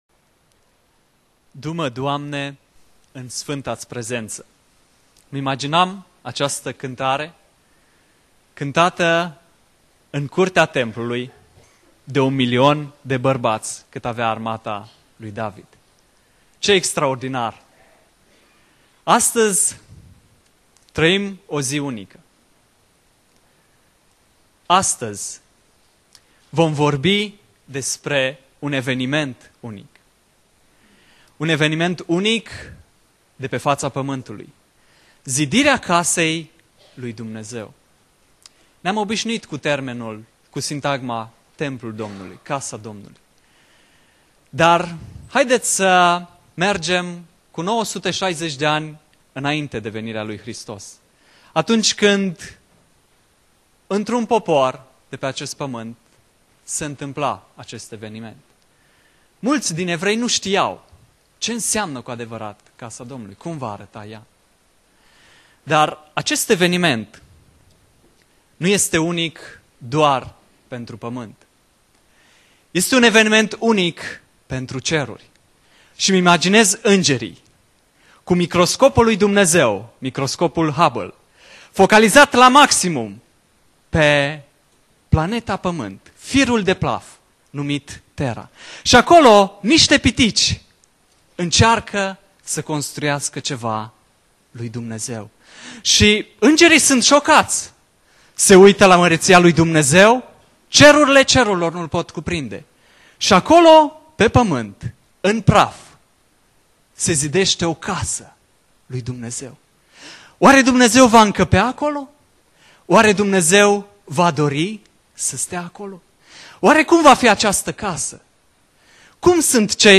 Predica Exegeza - 1 Imparati Cap 5-7